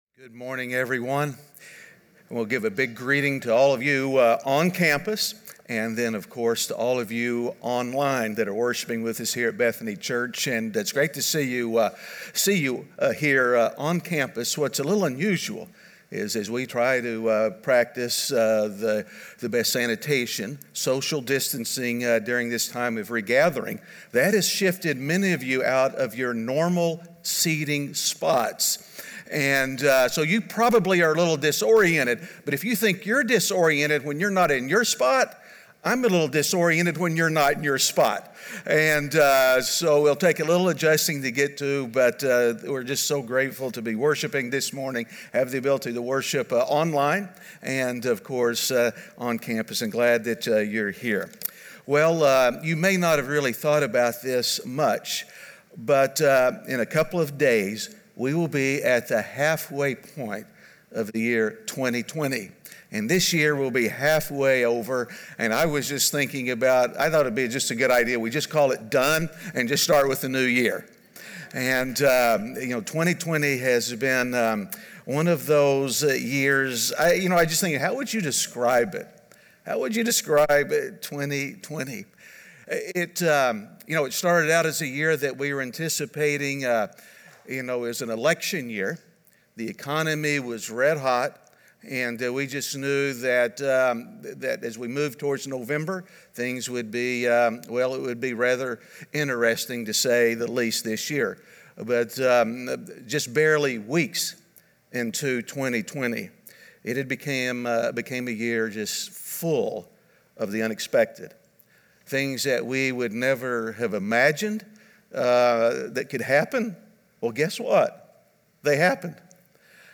Good Grief (Week 4) - Sermon.mp3